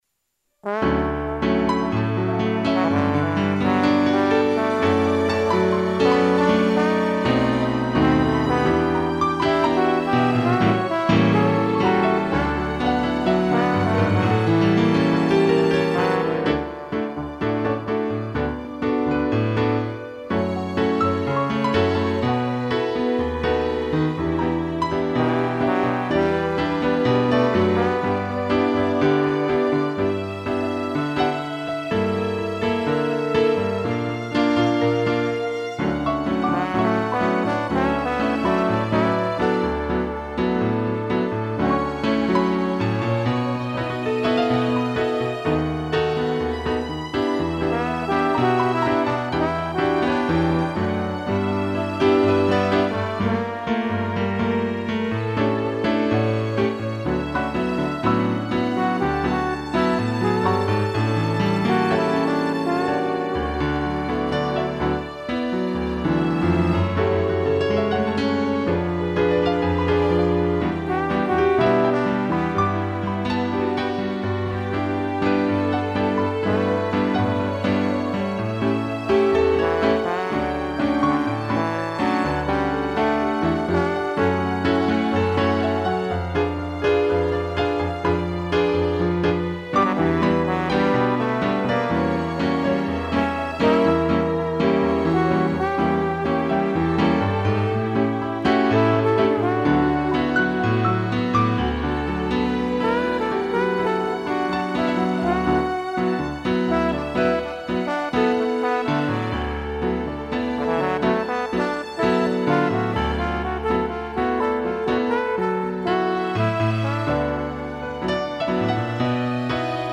2 pianos, trombone e strings
(instrumental)